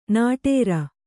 ♪ nāṭēra